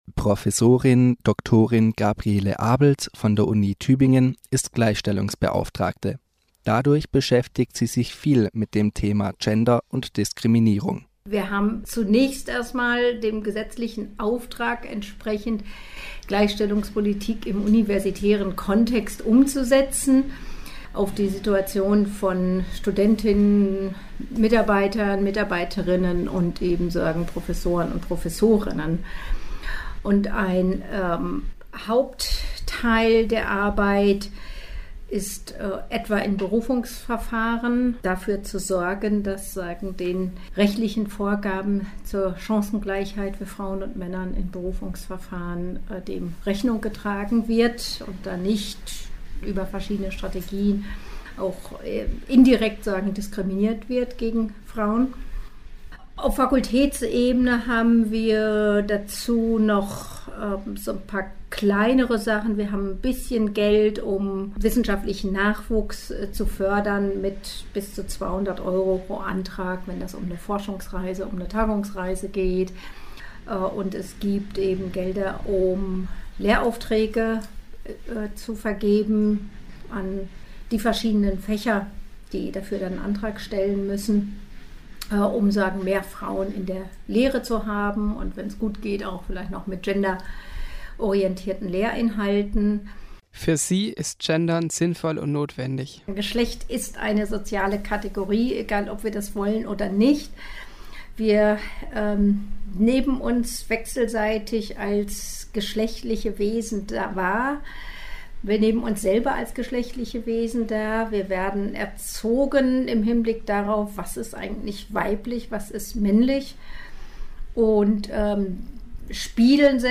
Das Interview wurde im Februar 2016 geführt.